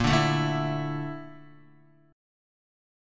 Listen to A#M#11 strummed